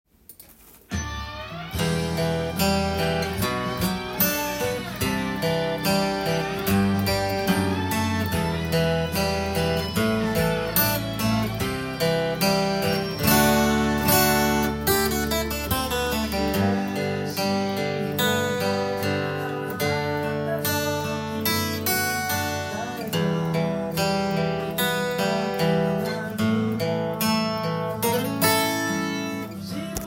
音源にあわせて譜面通り弾いてみました
アコースティックギターでアルペジオが合いそうな
イントロは、カンタンなコードのアルペジオから始まりますが
Aコードの部分でプリングを入れたフレーズを少し入れてみました。
Gのメジャースケールのスケール弾きを１６分音符で